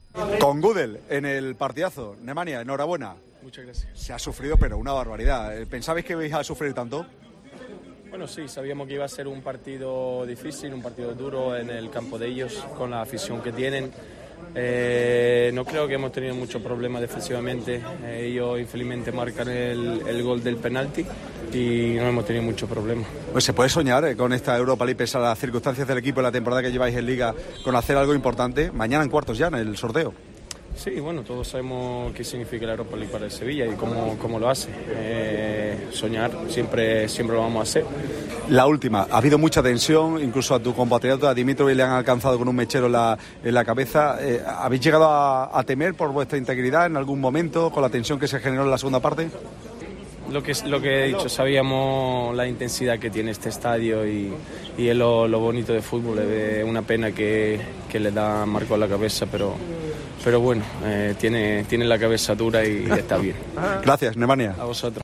habla con el centrocampista del Sevilla tras la clasificación para los cuartos de final de la Europa League.